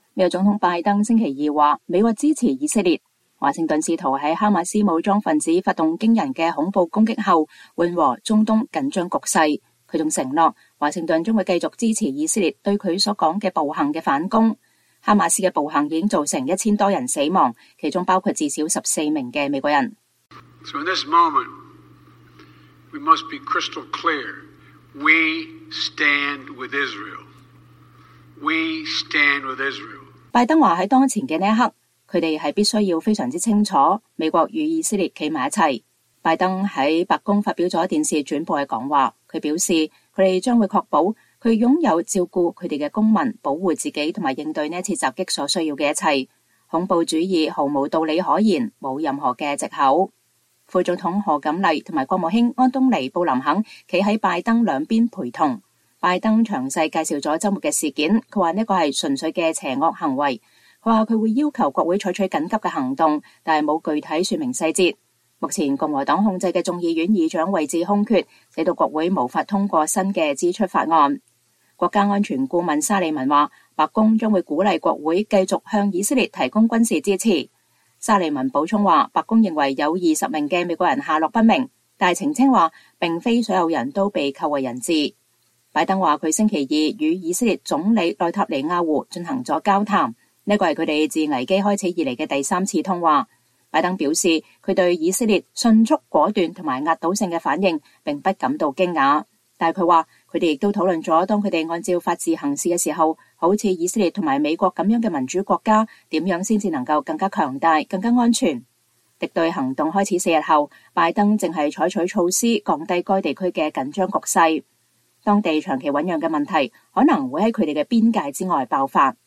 拜登發表電視講話 承諾支持以色列